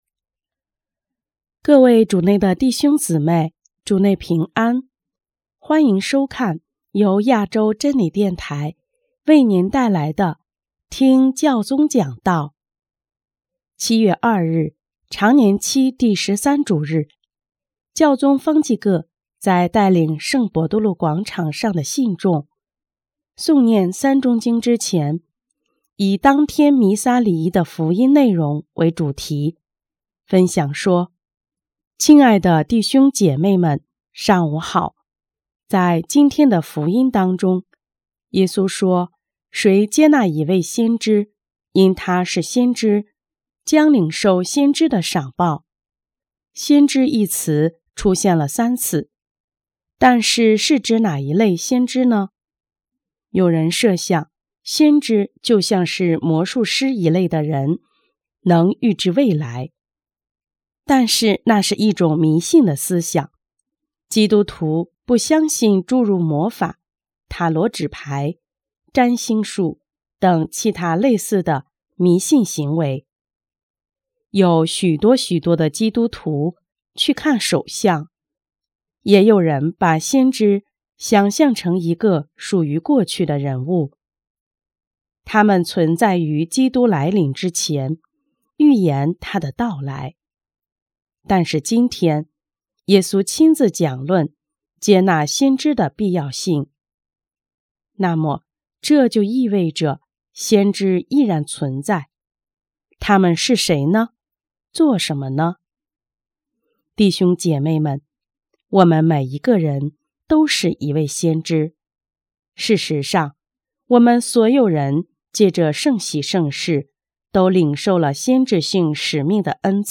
7月2日，常年期第十三主日，教宗方济各在带领圣伯多禄广场上的信众诵念《三钟经》之前，以当天弥撒礼仪的福音内容为主题，分享说：